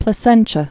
(plə-sĕnchə, -shə)